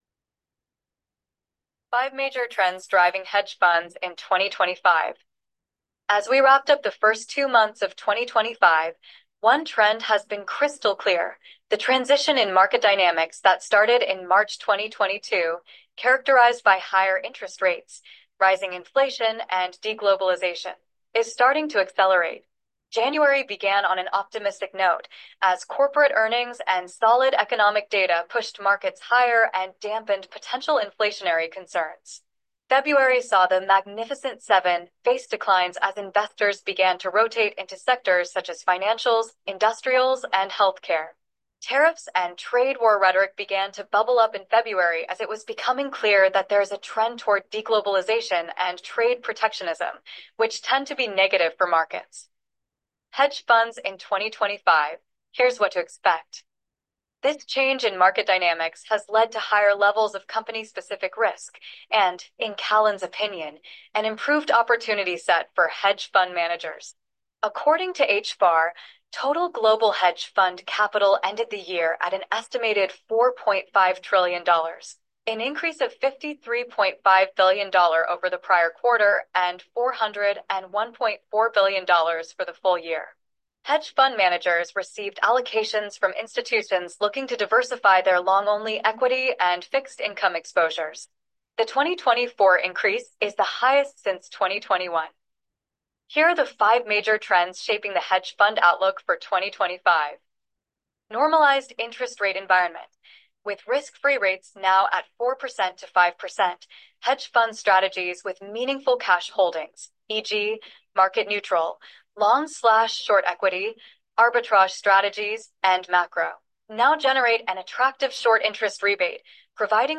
hedge-fund-outlook-blog-post-recording.m4a